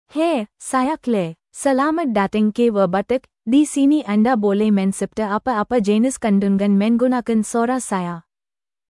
ClaireFemale Malayalam AI voice
Claire is a female AI voice for Malayalam (India).
Voice sample
Listen to Claire's female Malayalam voice.
Female
Claire delivers clear pronunciation with authentic India Malayalam intonation, making your content sound professionally produced.